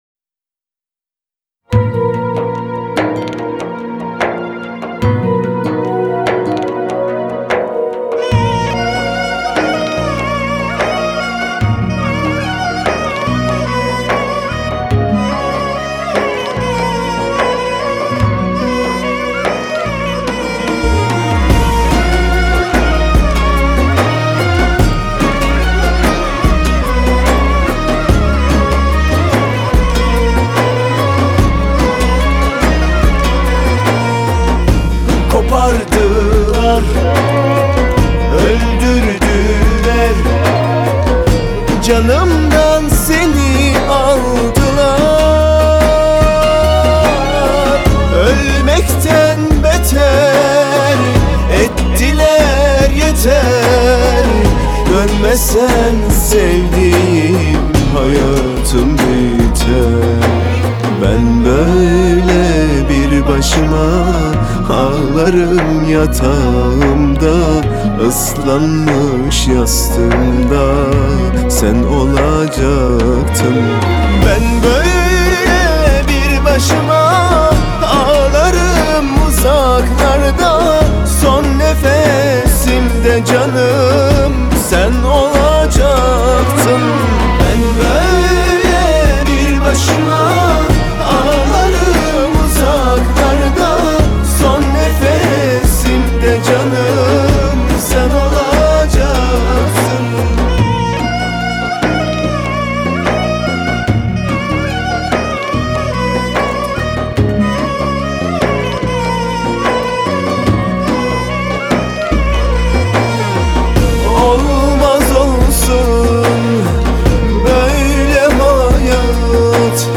Tür: Türkçe / Pop